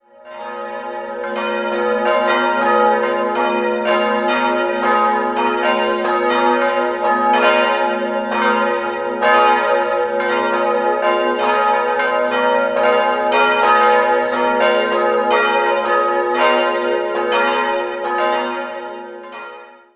3-stimmiges Paternoster-Geläute: g'-a'-h' Die beiden großen Glocken wurden um das Jahr 1500 in Nürnberg gegossen, die kleinste Glocke stammt ebenfalls aus Nürnberg und dürfte im zweiten Viertel des 16. Jahrhunderts entstanden sein .